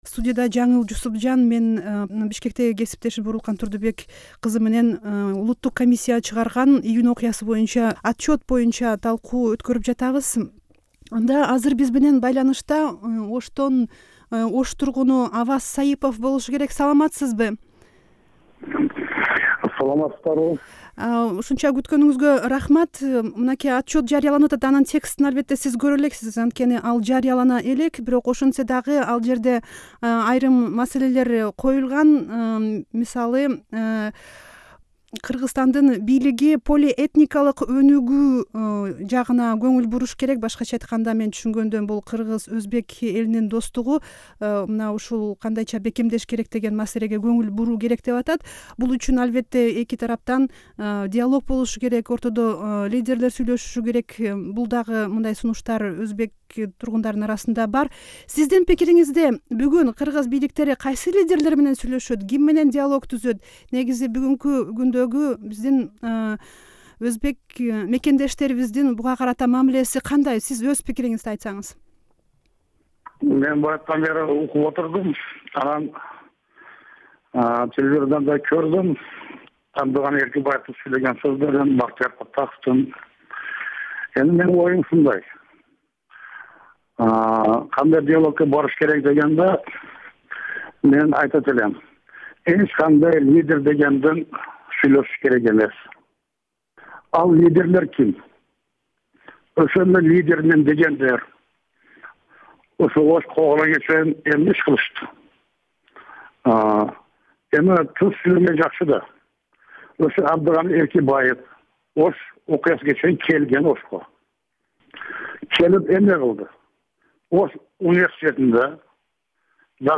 Round table on National Report over June Conflict, Jan 11, 2011.
Prague-Bishkek, Kyrgyzstan.